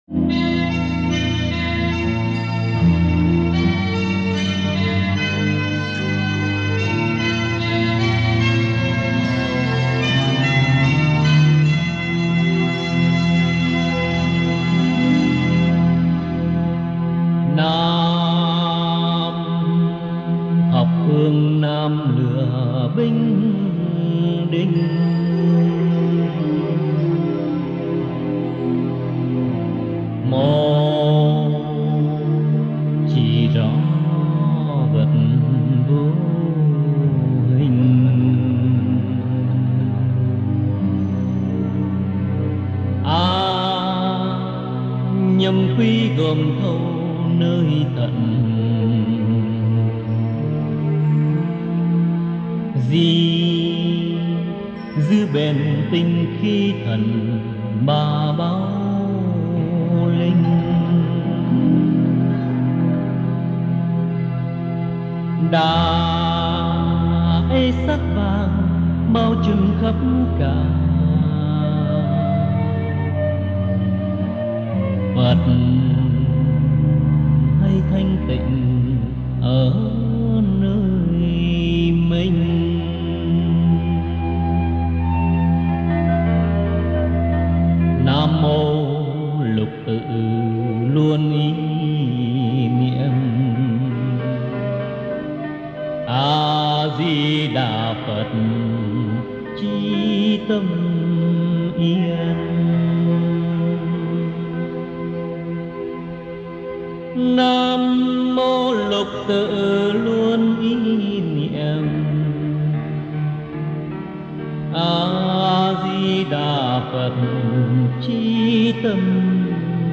Thiền Ca Vô Vi - Dân Ca & Cải Lương